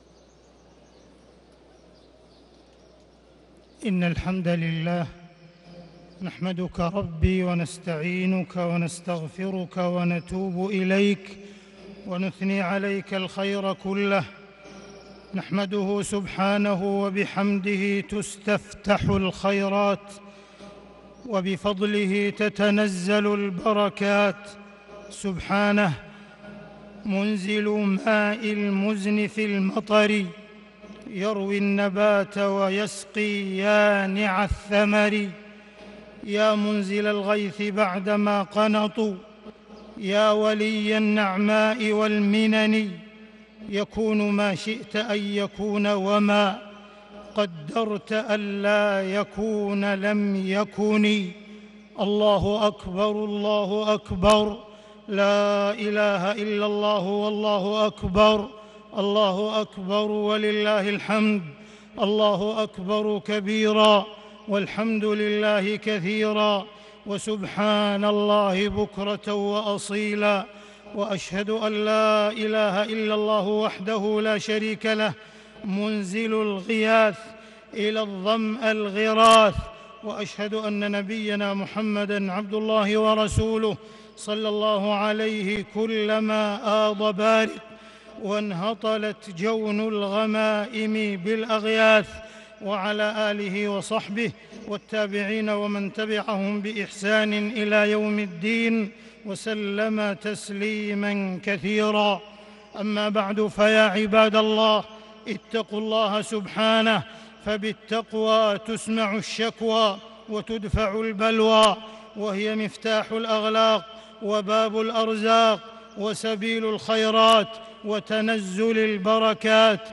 خطبة الإستسقاء 7-5-1441هـ > خطب الاستسقاء 🕋 > المزيد - تلاوات الحرمين